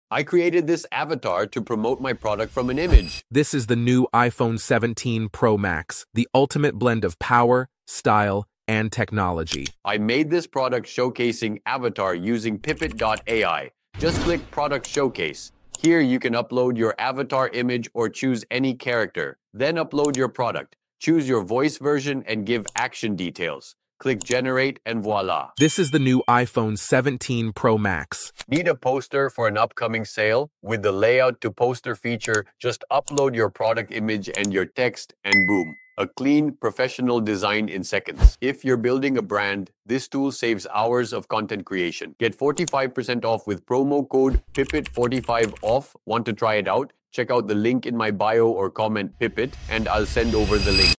Just made this super-realistic AI avatar with Pippit AI to promote a product—and yep, it talks exactly like me 👀 If you're in ecom, it can even model your product & pitch it like a pro.